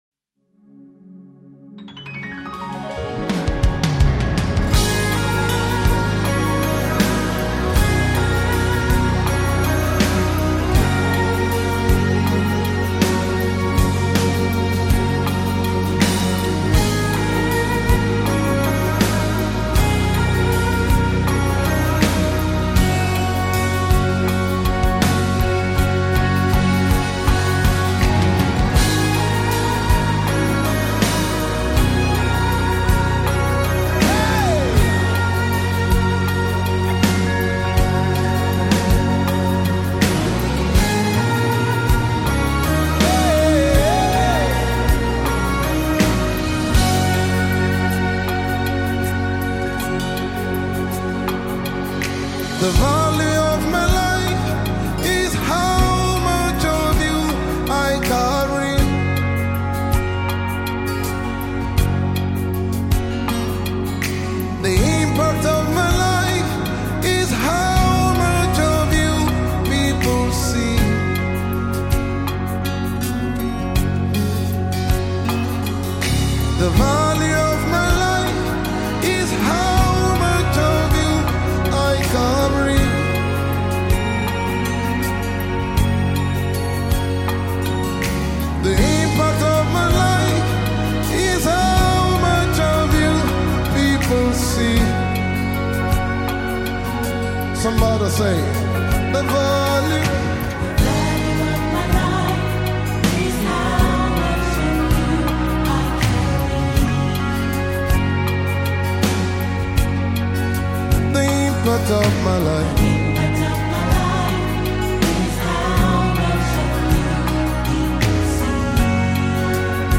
Gospel artist
soul lifting worship track
live music video